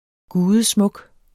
Udtale [ ˈguːðəˌ- ]